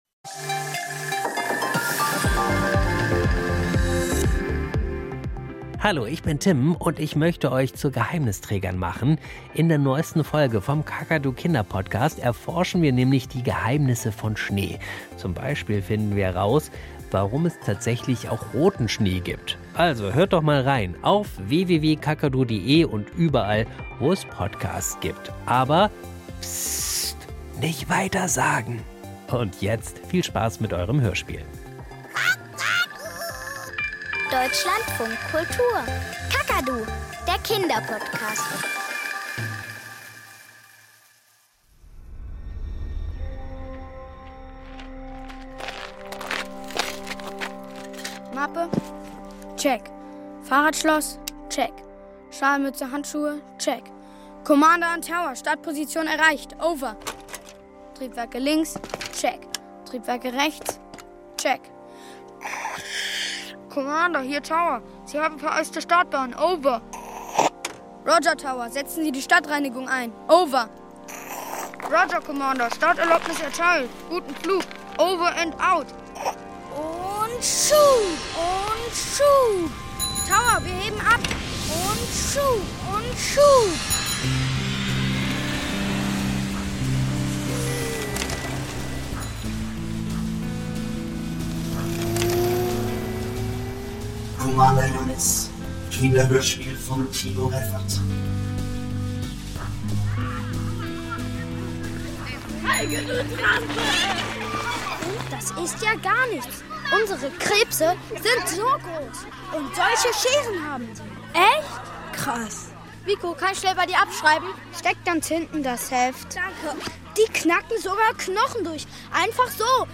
Kinderhörspiel - Commander Jannis ~ Kakadu – Das Kinderhörspiel Podcast